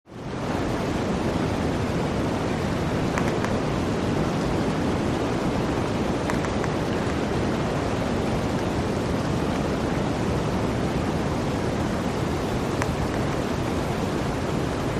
Free Nature sound effect: Snowstorm.
Snowstorm
# snow # blizzard # winter About this sound Snowstorm is a free nature sound effect available for download in MP3 format.
078_snowstorm.mp3